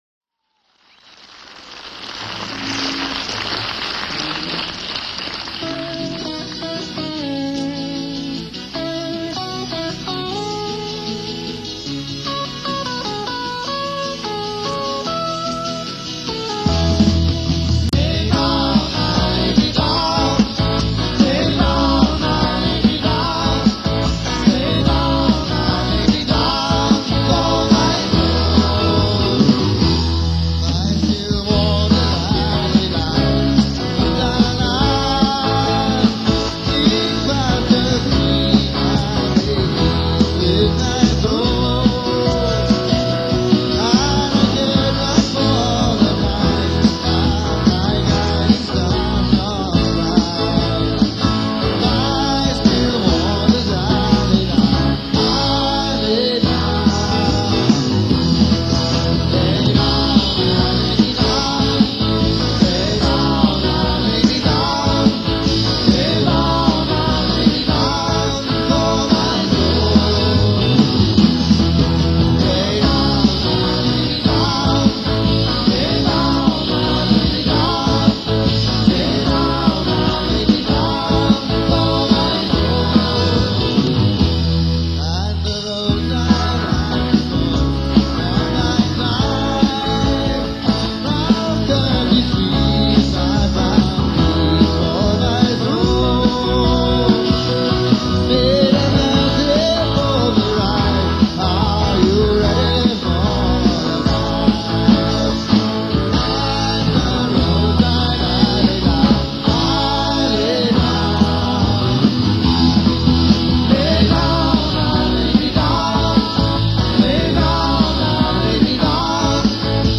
or have been fans of Progrock for a while.